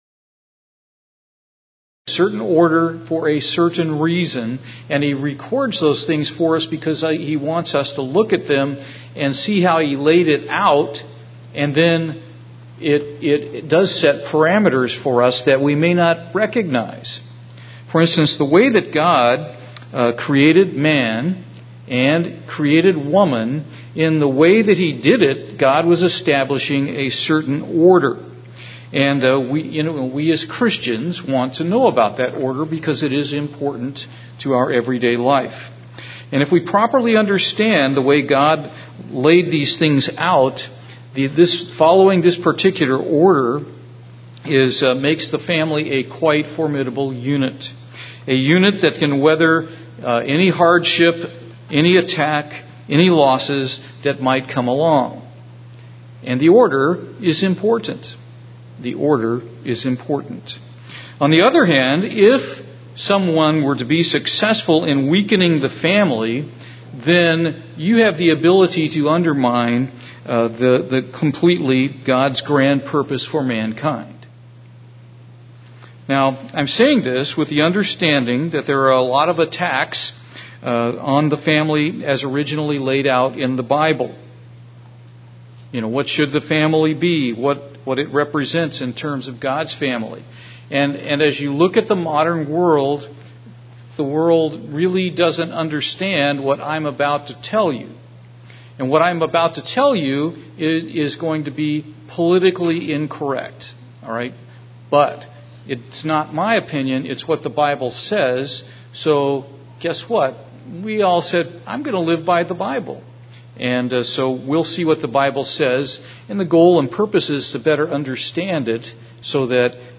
This sermon begins a two sermon series on marriage.